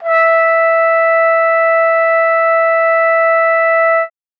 Index of /90_sSampleCDs/Best Service ProSamples vol.42 - Session Instruments [AIFF, EXS24, HALion, WAV] 1CD/PS-42 WAV Session Instruments/Trumpet piano